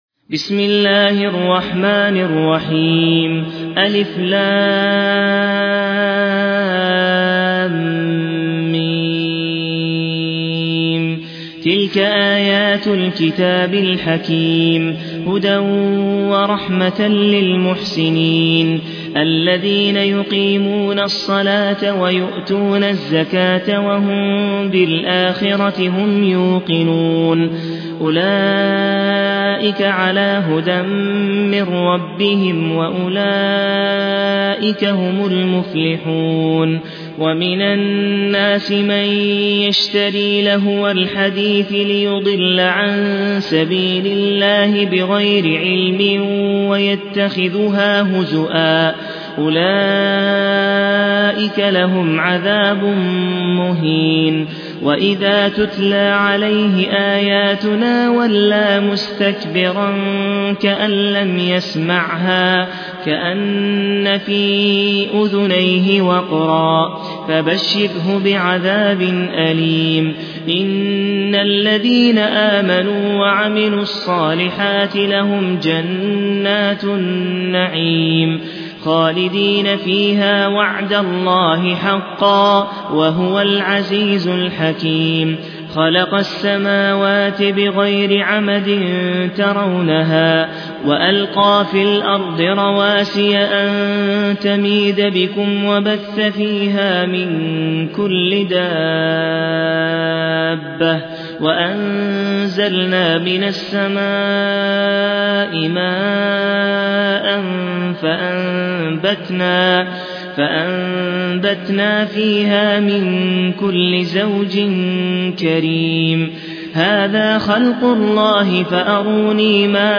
المصحف المرتل - شعبة عن عاصم بن أبي النجود